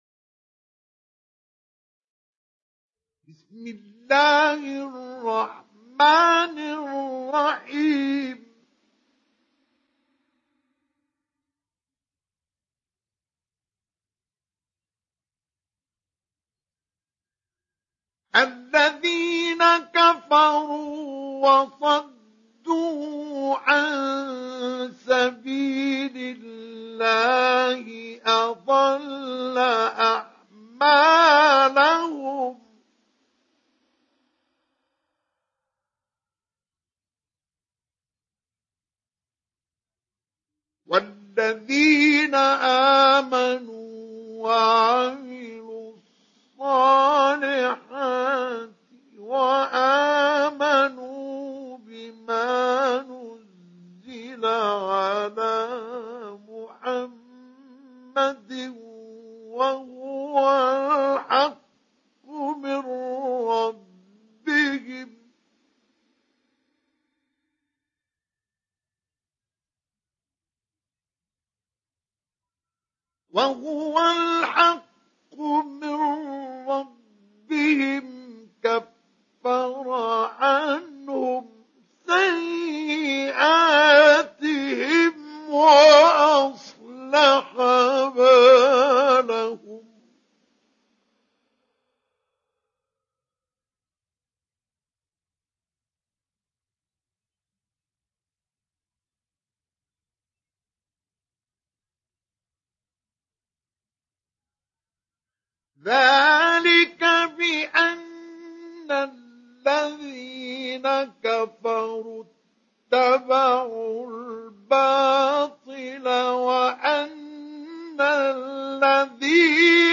Sourate Muhammad Télécharger mp3 Mustafa Ismail Mujawwad Riwayat Hafs an Assim, Téléchargez le Coran et écoutez les liens directs complets mp3
Télécharger Sourate Muhammad Mustafa Ismail Mujawwad